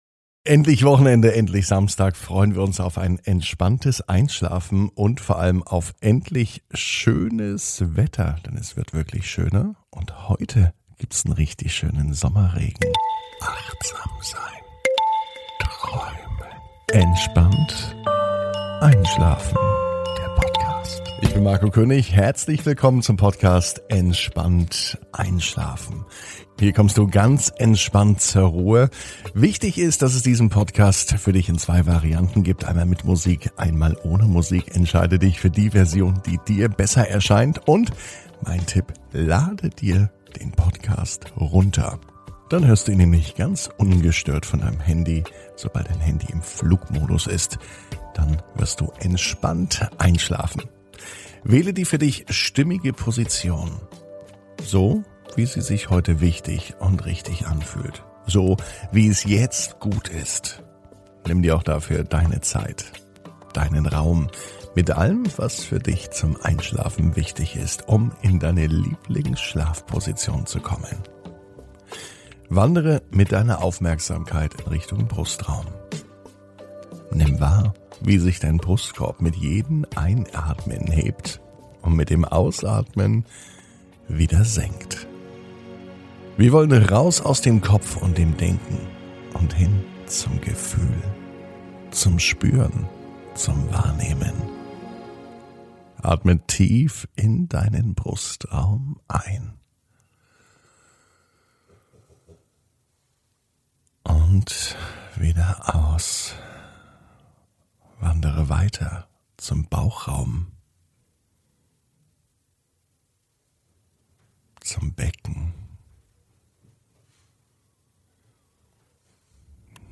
(ohne Musik) Entspannt einschlafen am Samstag, 29.05.21 ~ Entspannt einschlafen - Meditation & Achtsamkeit für die Nacht Podcast